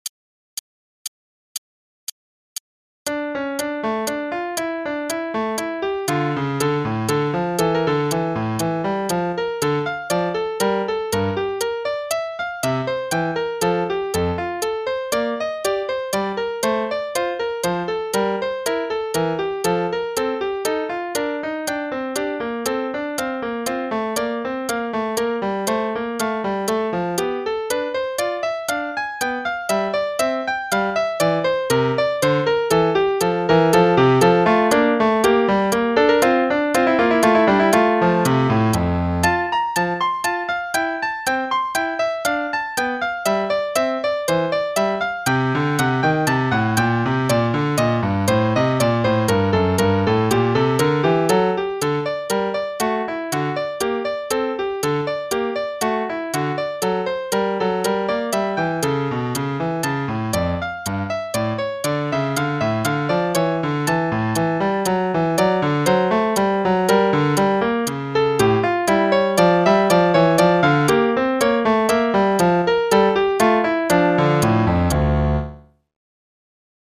Play-along: Prelude in D minor (en=102)